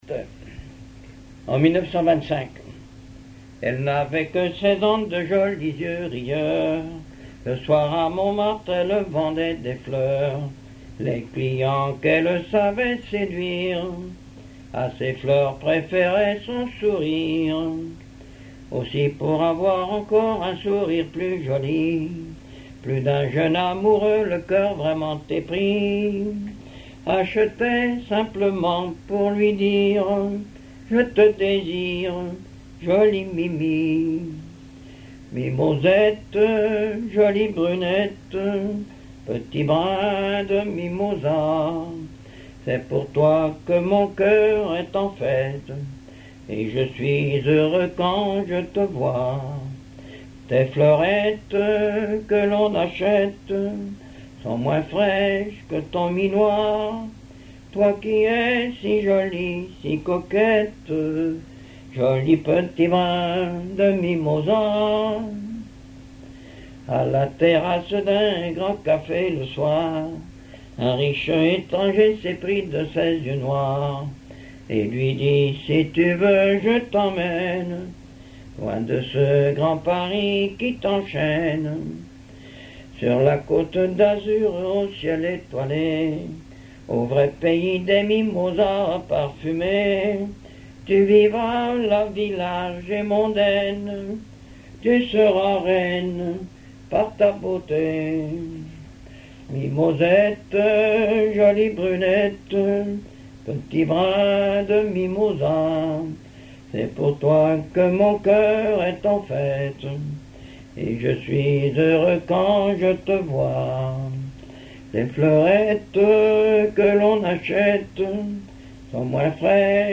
Genre strophique
chansons populaires et histoires drôles
Pièce musicale inédite